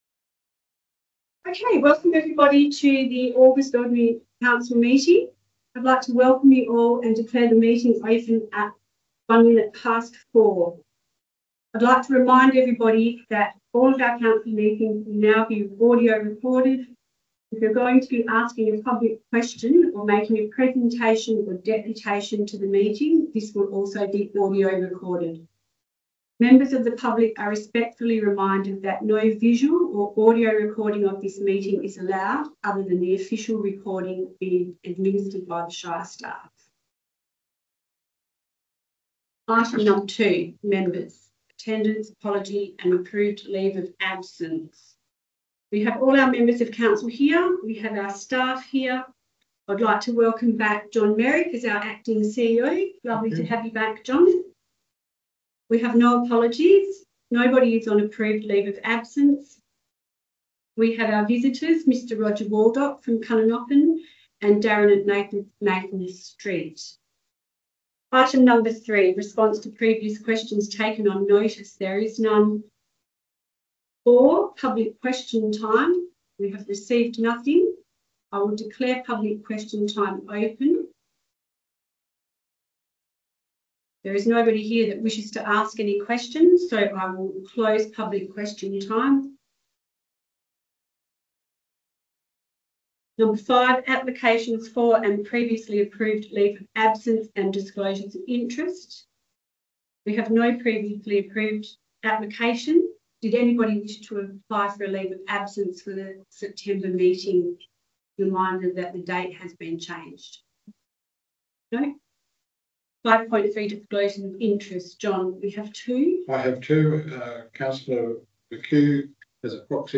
20 August 2025 Ordinary Meeting of Council » Shire of Trayning